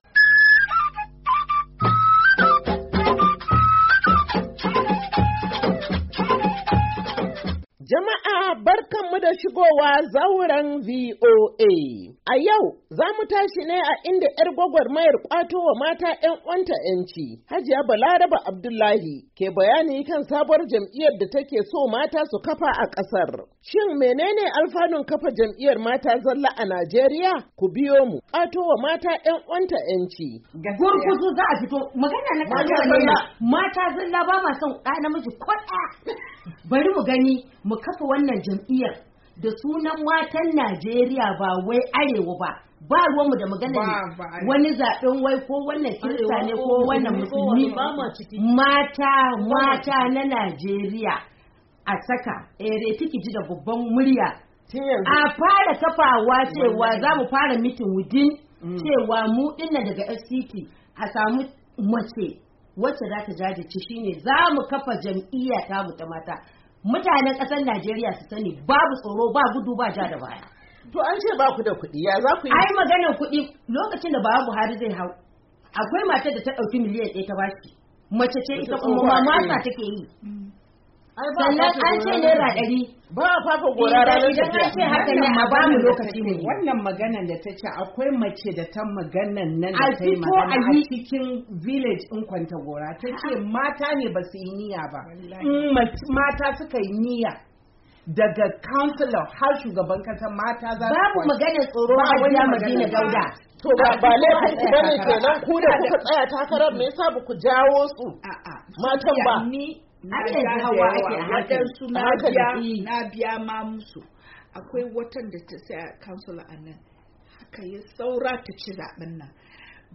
ZAUREN VOA: Ci gaba Da Tattaunawa Da Wasu Fitattun Mata 'Yan Siyasa A Najeriya, 21 Mayu, 2023